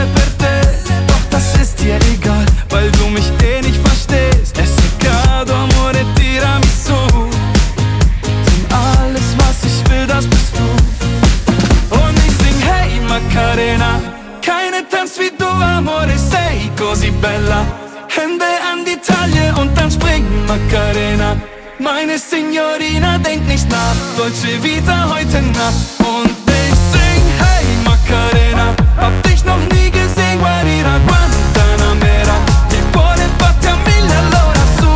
Жанр: Поп музыка
German Pop